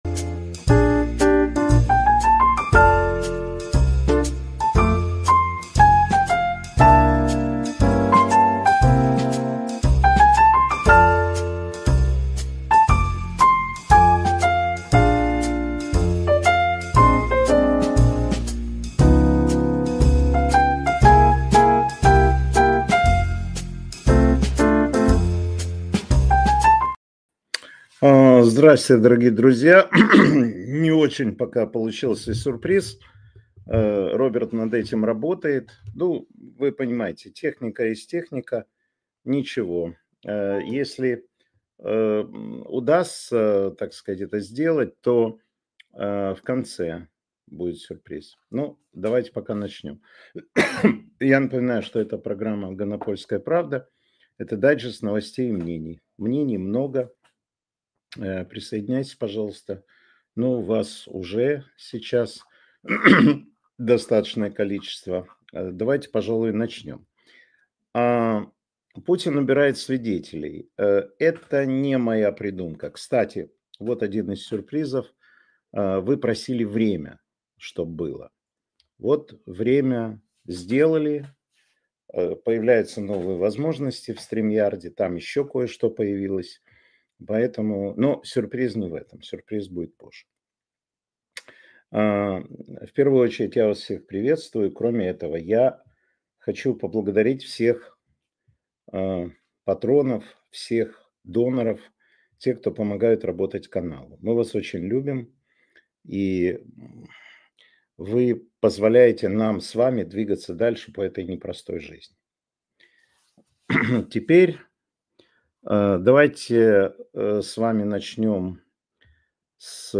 Эфир ведёт Матвей Ганапольский